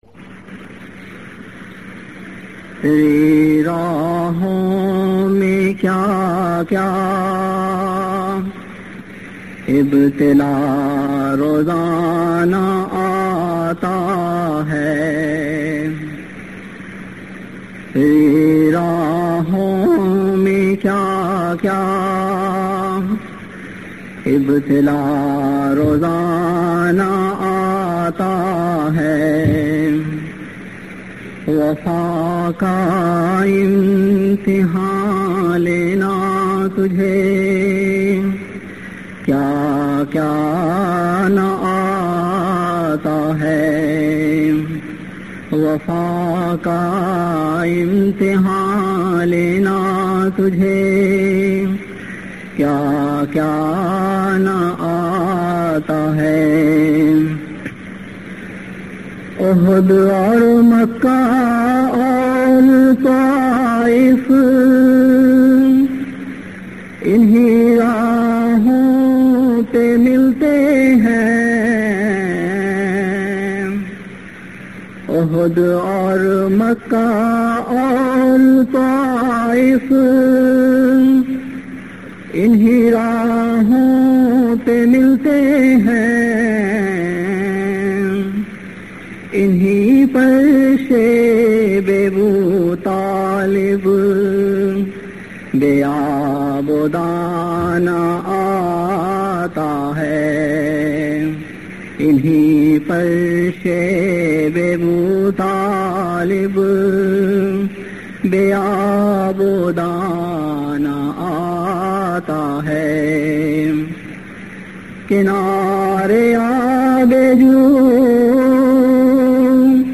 نظمیں (Urdu Poems)
آواز: ممبر خدام الاحمدیہ Voice: Member Khuddamul Ahmadiyya